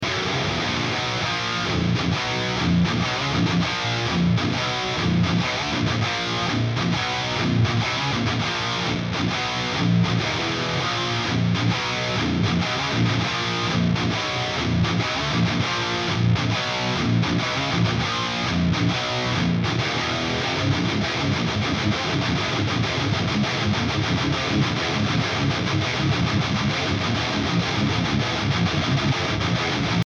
Сегодня доделал свой мегажирный пресет Mesa boogie примеры в сыром миксе , и без ) все без обработки ,включая гитары в тонклоуде искать Zero mesa fat v2 Вложения Zero mesa guitars(2).mp3 Zero mesa guitars(2).mp3 1,1 MB · Просмотры: 560 Zero mesa v2(2).mp3 Zero mesa v2(2).mp3 1,1 MB · Просмотры: 484